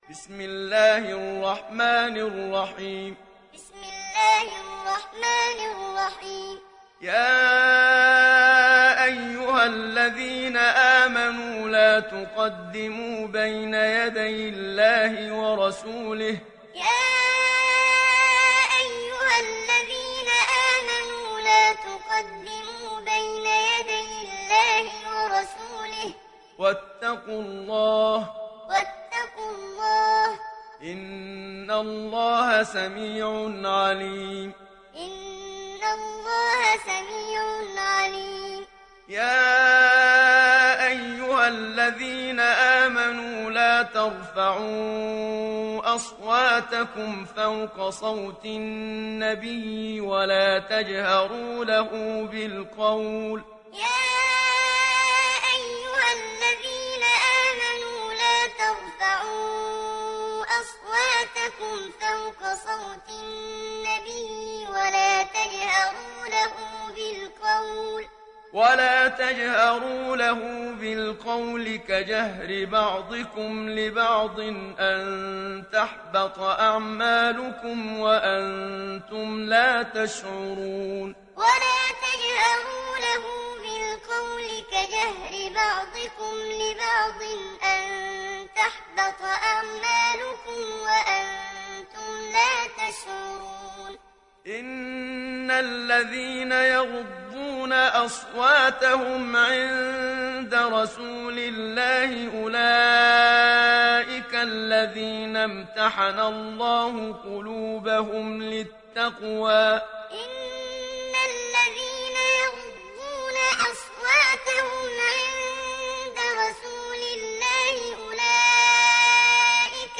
সূরা আল-হুজুরাত mp3 ডাউনলোড Muhammad Siddiq Minshawi Muallim (উপন্যাস Hafs)